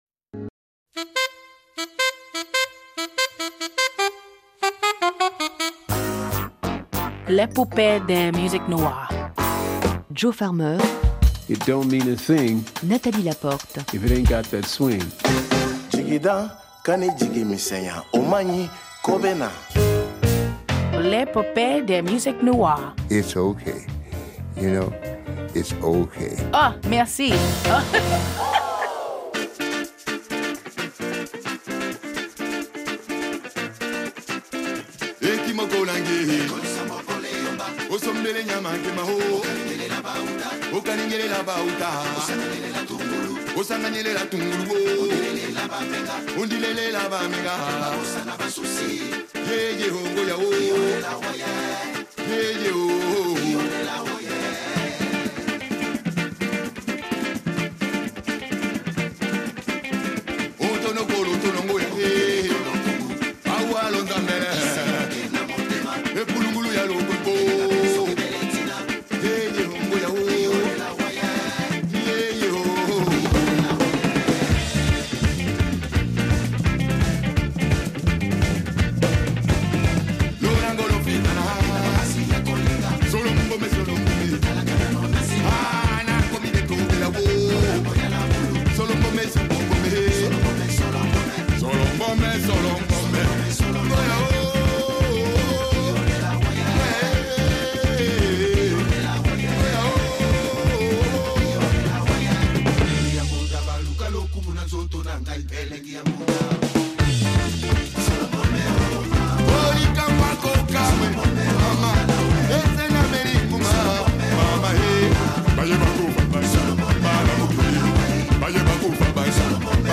Le XXIè siècle voit le jaillissement créatif de nouveaux musiciens et interprètes dont la hardiesse n’émousse pas un profond respect pour la tradition. Lors du 37è festival « Jazz en Tête » à Clermont-Ferrand, le jeune pianiste américain